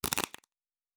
Cards Shuffle 1_07.wav